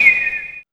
2404L WHISFX.wav